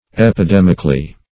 epidemically - definition of epidemically - synonyms, pronunciation, spelling from Free Dictionary Search Result for " epidemically" : The Collaborative International Dictionary of English v.0.48: Epidemically \Ep`i*dem"ic*al*ly\, adv. In an epidemic manner.